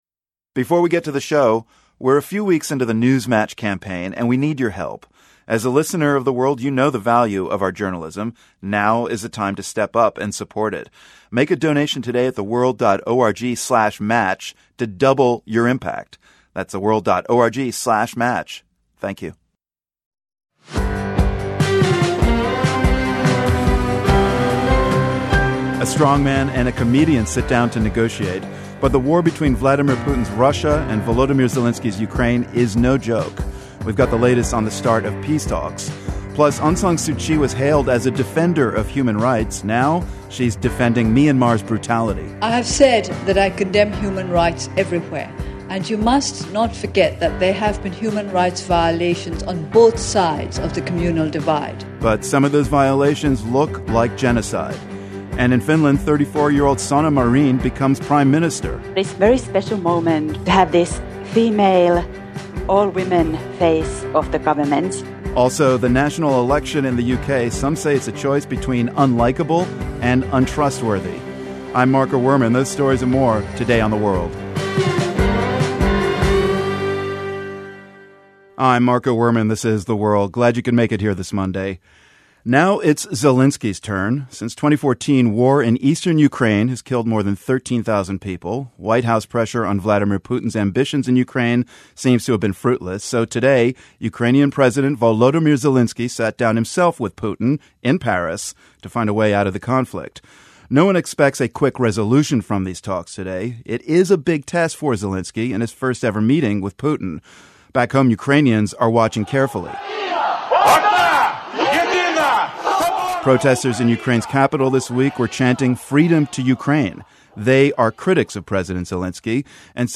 The World is a public media news program that relies on the support of listeners like you.